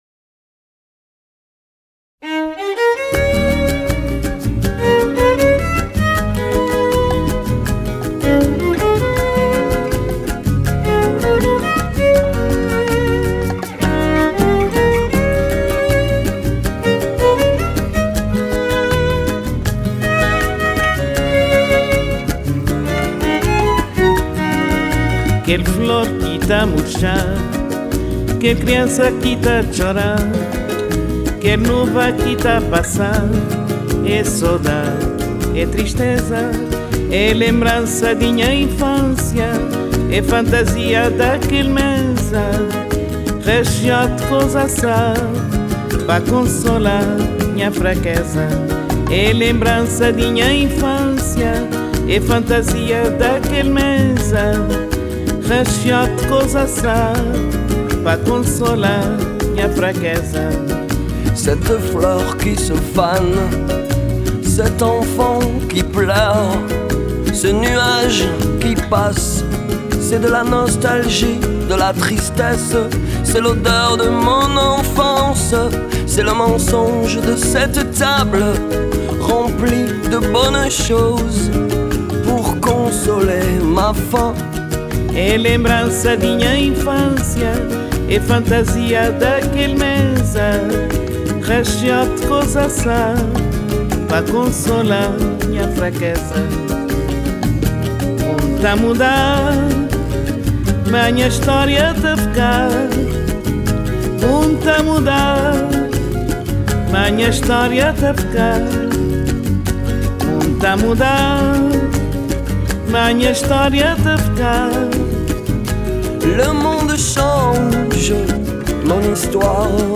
Под музыку, напоминающую смесь шансонных традиций и босановы